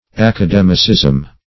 Academicism \Ac`a*dem"i*cism\, n.